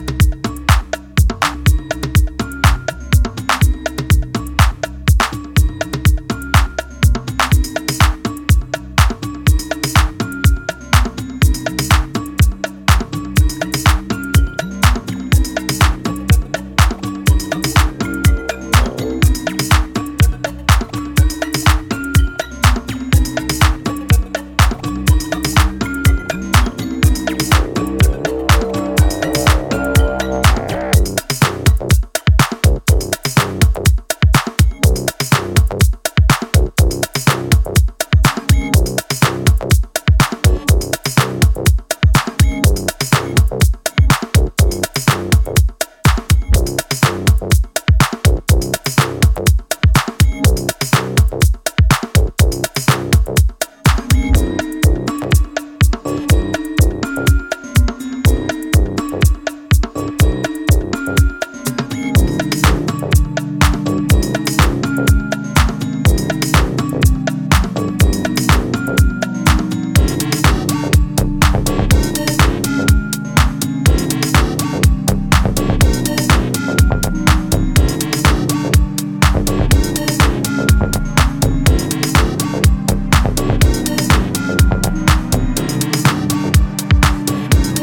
quirky, funky and gripping composition